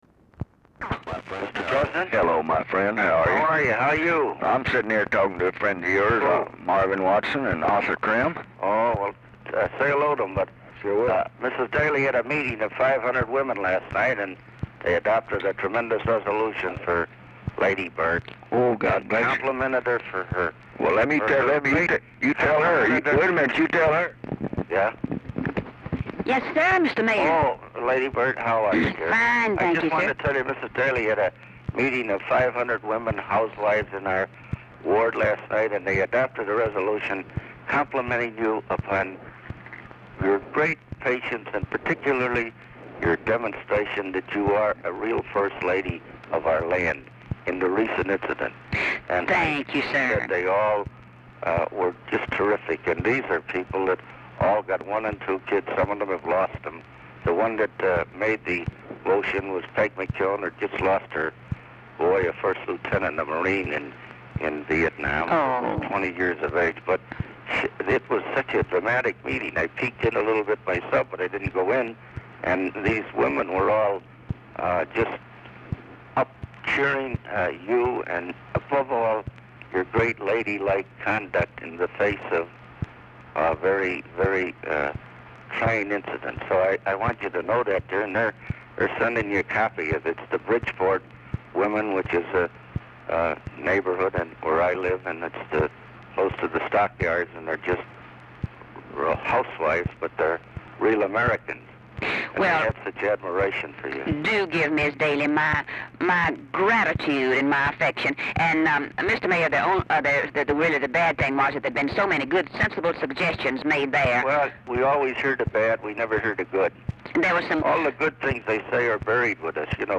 Telephone conversation
RECORDING ENDS BEFORE CONVERSATION IS OVER
Dictation belt
Mansion, White House, Washington, DC